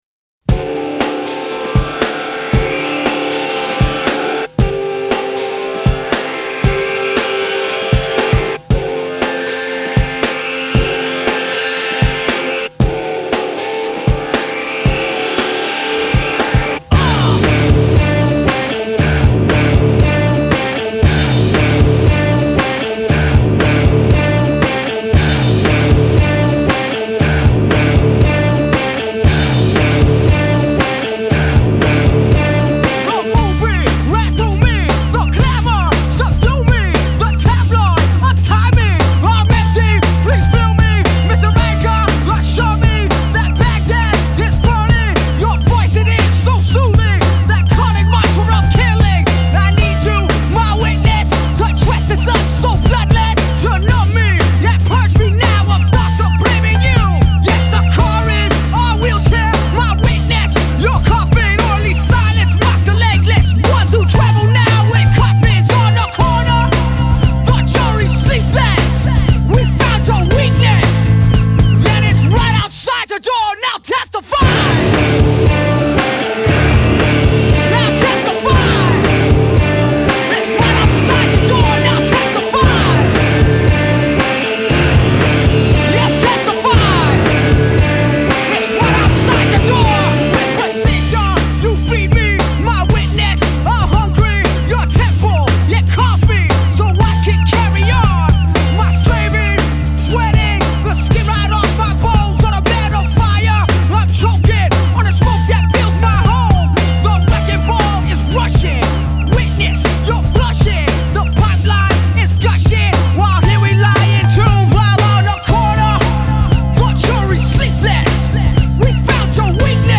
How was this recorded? rare studio version